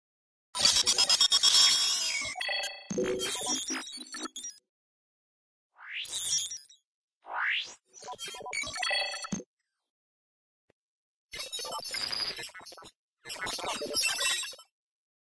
Category:Lonesome Road endgame narrations Du kannst diese Datei nicht überschreiben.